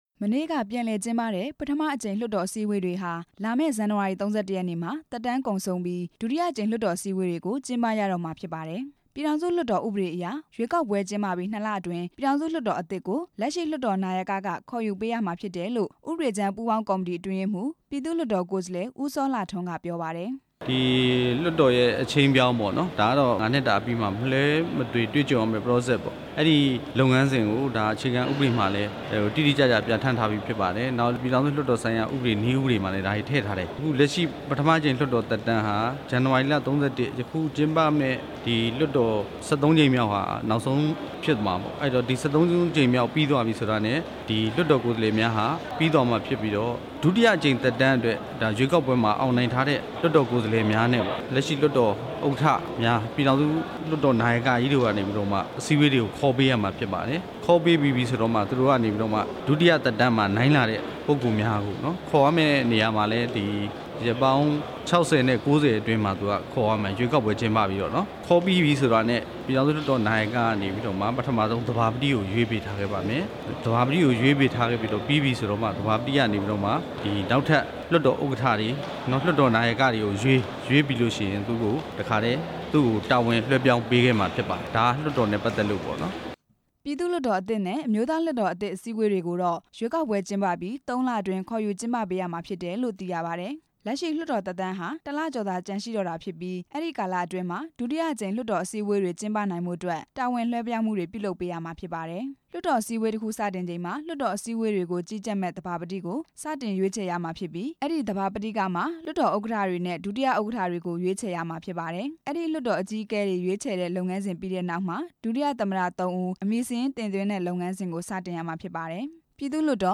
လက်ရှိအစိုးရသက်တမ်းအတွင်း နောက်ဆုံးအကြိမ် ကျင်းပတဲ့ လွှတ်တော်ဟာ လာမယ့် ဇန်နဝါရီလ ၃၁ ရက်နေ့မှာ သက်တမ်းကုန်ဆုံးမှာဖြစ်ပြီး လွှတ်တော် အသစ်ကို လွဲှပြောင်းပေးရမှာဖြစ်ပါတယ်။ လွှတ်တော်အသစ်နဲ့ အစိုးရအသစ်ကို အာဏာ လွှဲပြောင်းပေးရမယ့် လုပ်ငန်းစဉ်တွေနဲ့ပတ်သက်ပြီး လက်ရှိလွှတ်တော် ကိုယ်စားလှယ်တွေကို မေးမြန်းပြီး